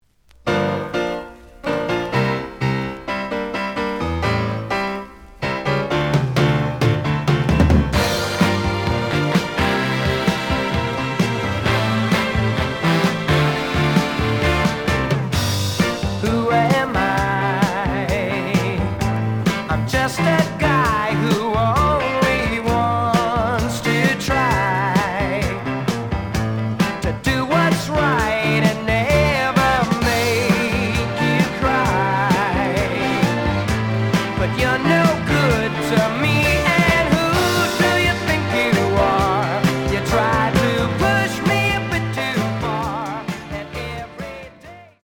試聴は実際のレコードから録音しています。
●Format: 7 inch
●Genre: Rock / Pop
傷は多いが、プレイはまずまず。)